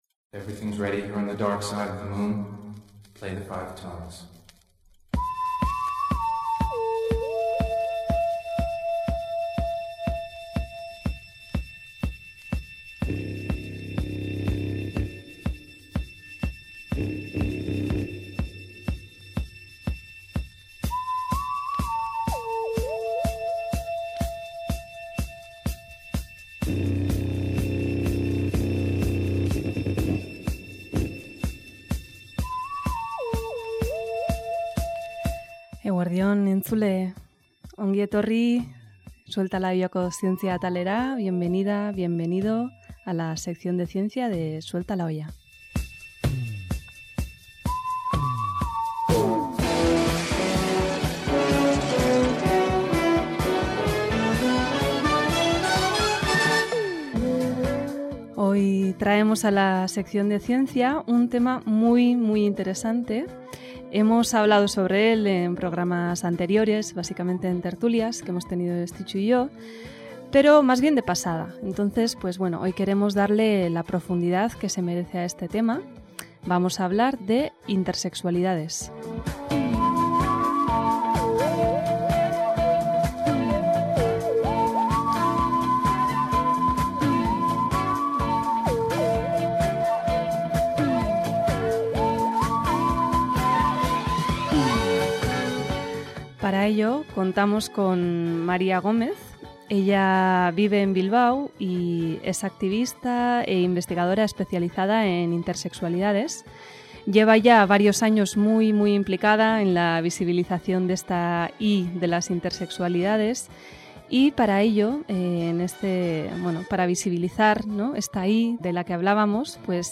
Pretendemos, con esta entrevista, ayudar a romper tabúes, acercando y visibilizando las realidades de estos cuerpos que transgreden la norma.